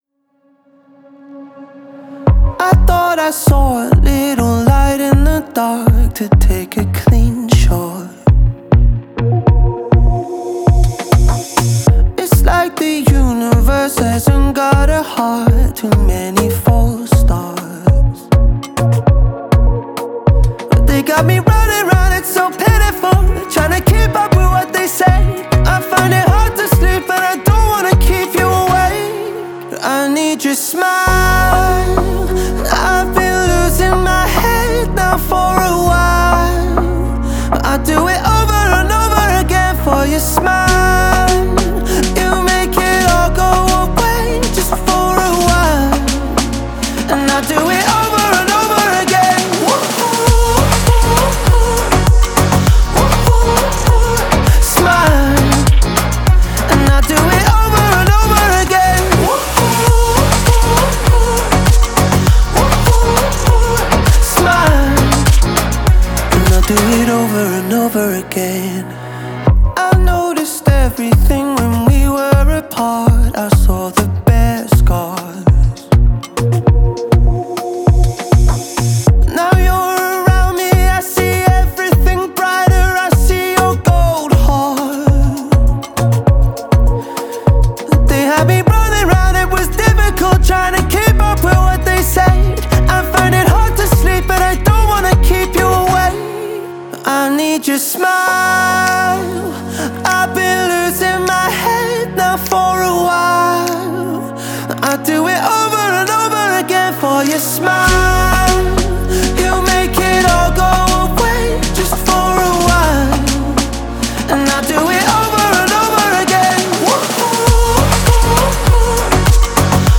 это зажигательная трек в жанре EDM